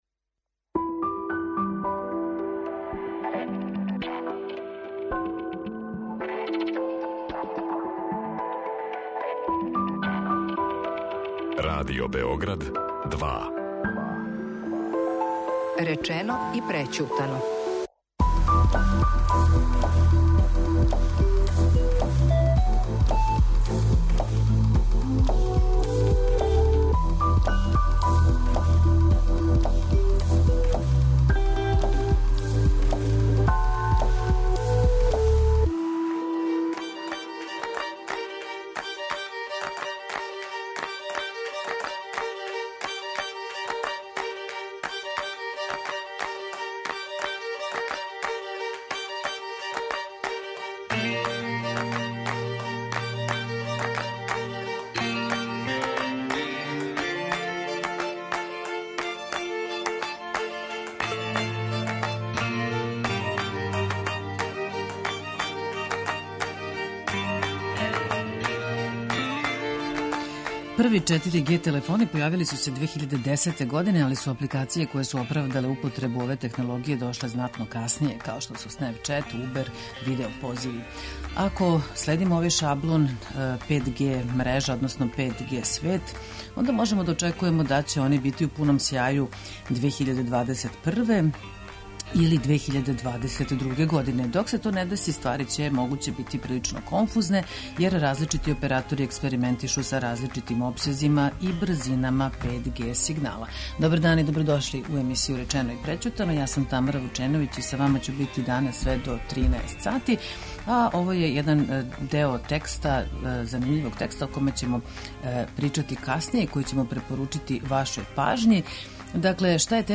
Са нама уживо: